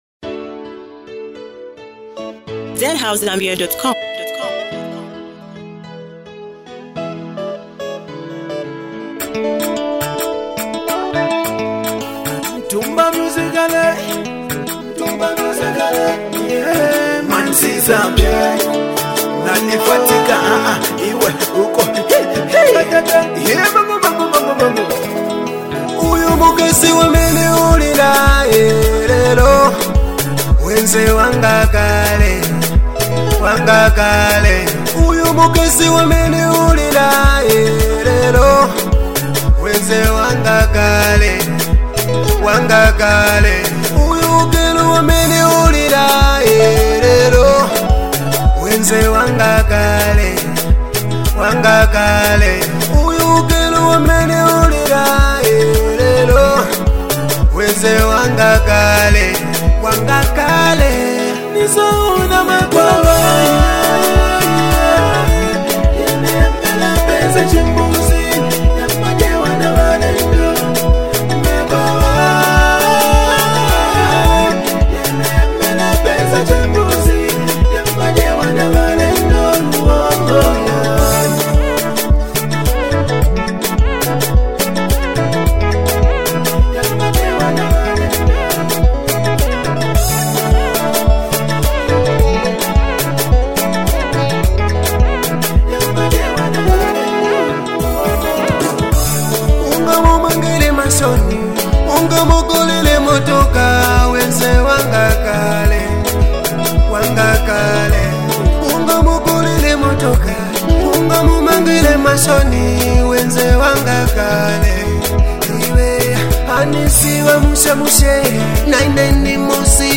A true emotional banger!